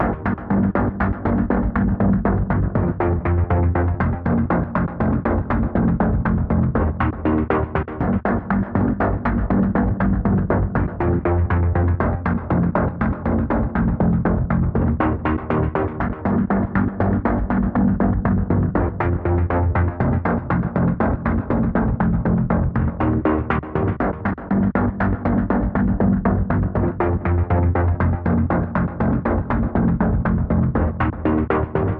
出神入化式的循环
Tag: 120 bpm Cinematic Loops Synth Loops 5.38 MB wav Key : Em FL Studio